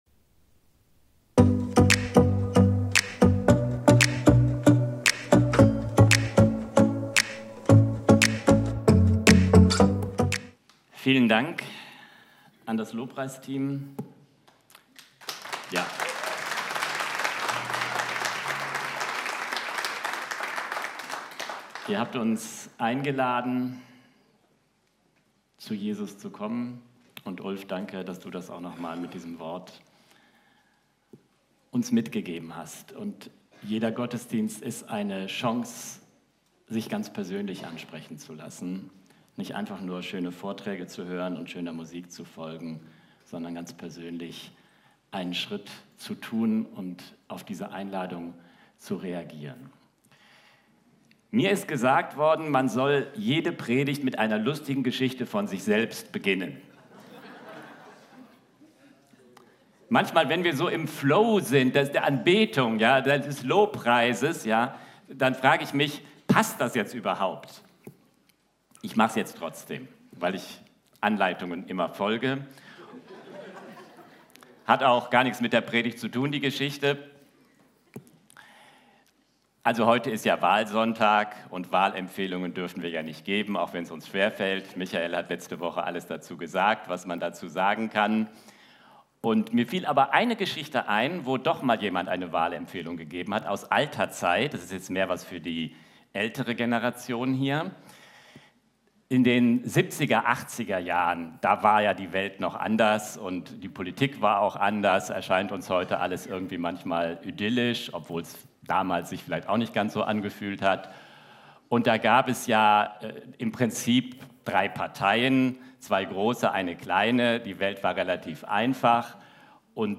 Video und MP3 Predigten
Kategorie: Sonntaggottesdienst Predigtserie: Jüngerschaft leben lernen